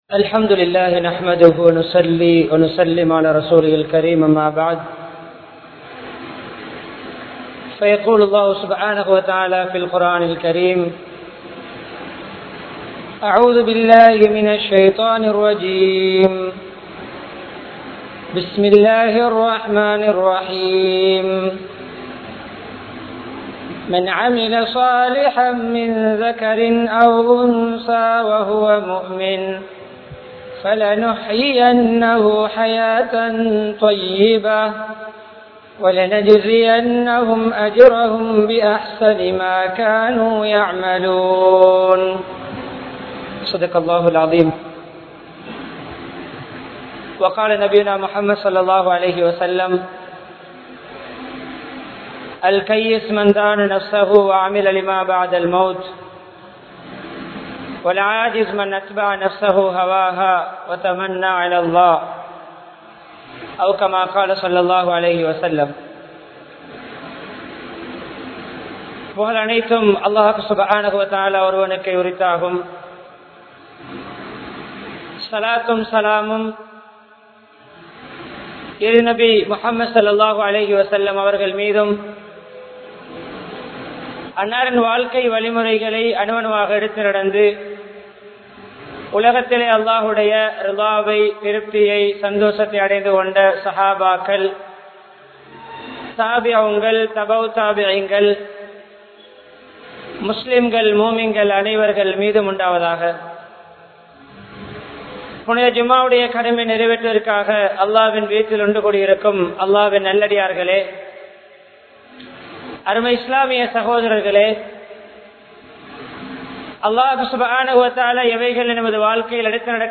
Puththisaalien Vaalkai Murai (புத்திசாலியின் வாழ்க்கை முறை) | Audio Bayans | All Ceylon Muslim Youth Community | Addalaichenai
Meeripenna Jumua Masjith